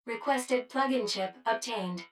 153_Chip_Obtained.wav